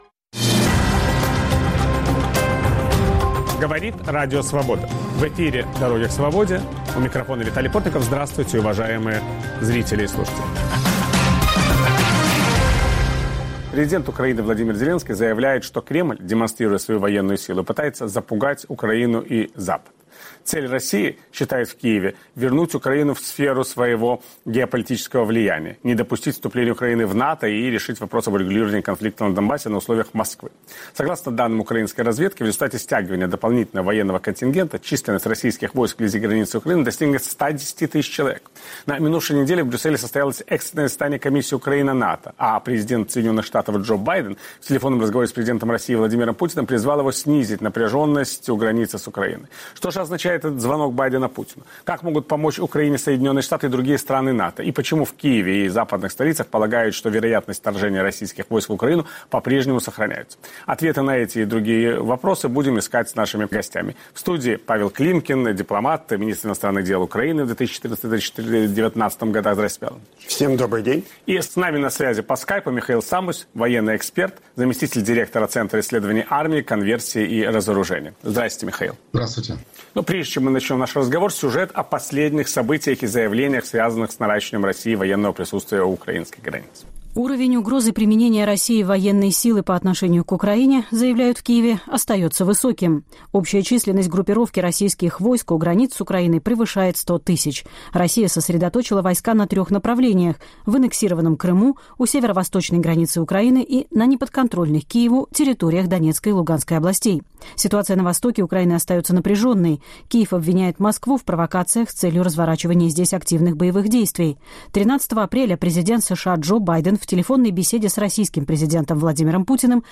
Удастся ли остановить эскалацию конфликта на востоке Украины? Виталий Портников беседует с бывшим министром иностранных дел Украины Павлом Климкиным